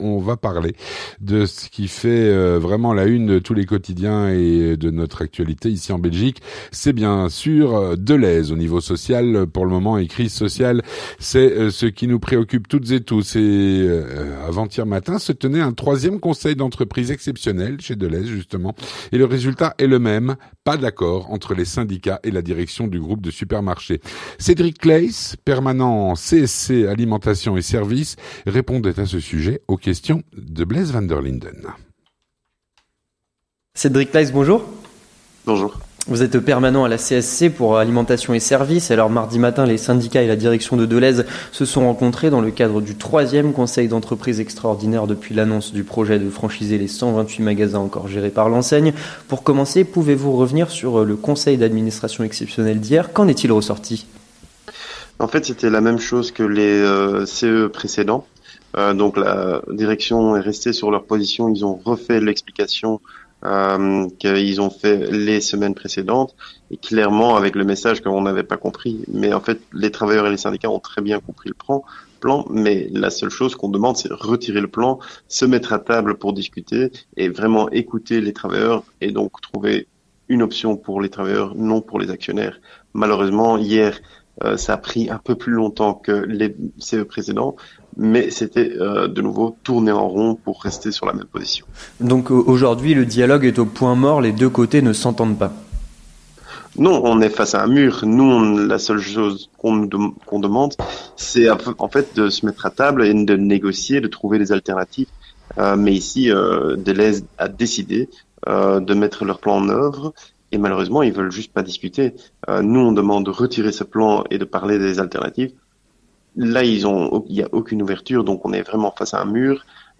Entretien du 18h - Les négociations aux point mort